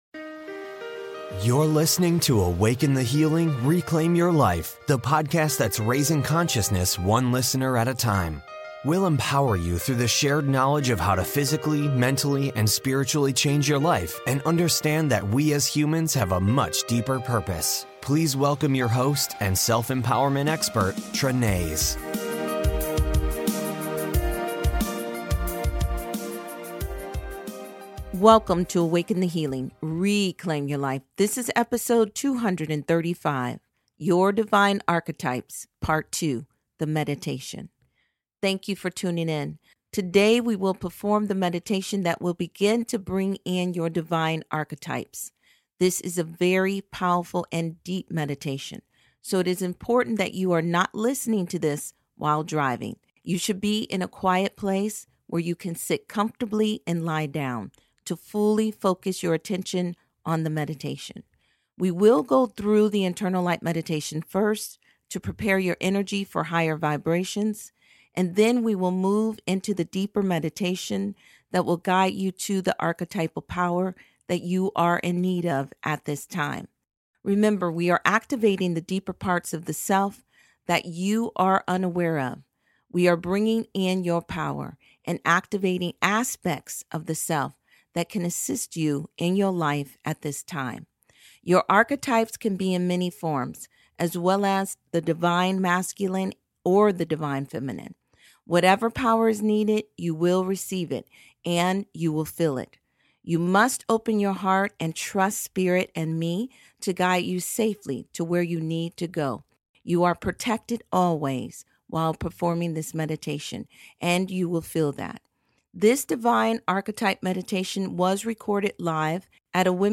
/ Episode 235 "Your Divine Archetypes" part 2 ~ The Meditation